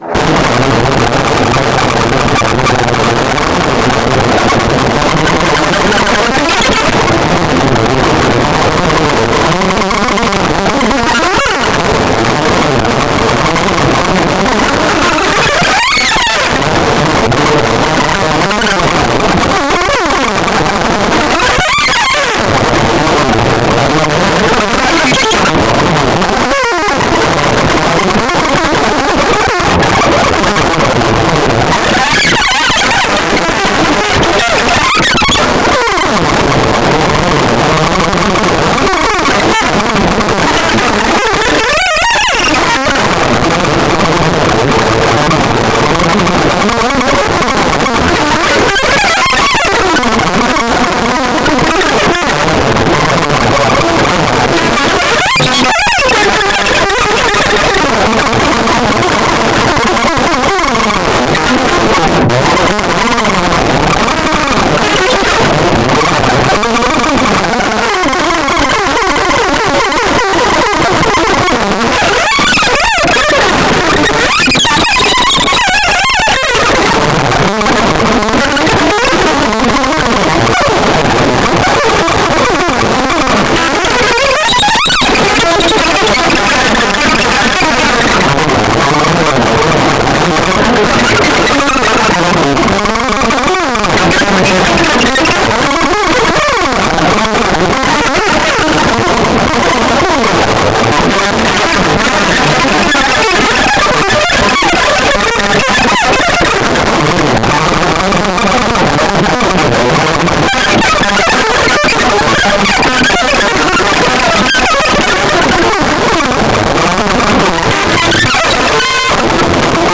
オーバー・ダビング、編集無しの、無伴奏ギター独奏、しかも全曲モノラル。
ギター愛好家はもちろん、圧倒的高エネルギー表現を愛するマニアにお勧め。
raw, fresh, crude, live, in-person
natural, wild, haggard, untamed, warrigal
fierce, savage, fell, tigerish, vicious-tempered